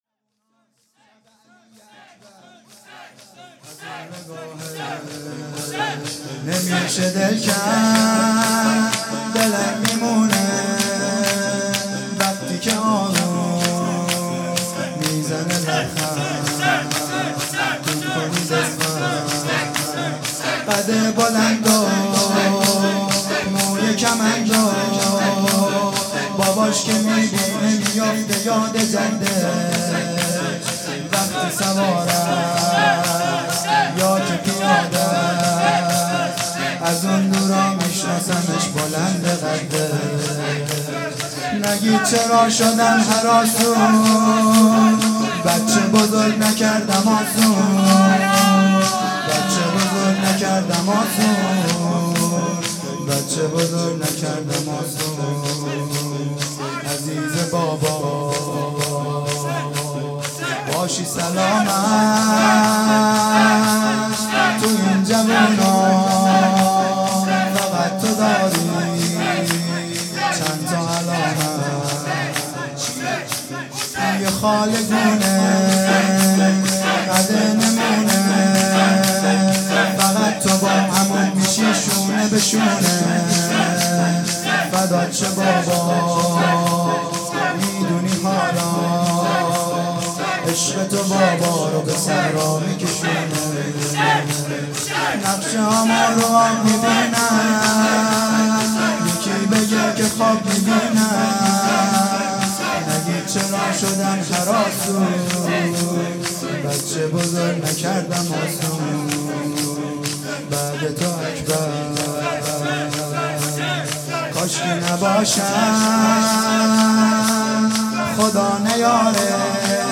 هیئت دانشجویی فاطمیون دانشگاه یزد
شب هشتم محرم